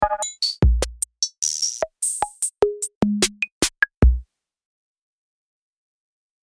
The CR-78 by TXVXN is a digital recreation of the legendary CR-78 drum machine, offering vintage rhythms with a modern twist. With meticulously crafted sounds and intuitive controls, it brings the warmth and character of classic drum machines to your digital production.
CR-78-KIT.mp3